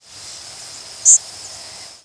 A finer buzz has faster modulations (e.g., Swamp Sparrow) and a coarser buzz has slower modulations (e.g.,
Indigo Bunting).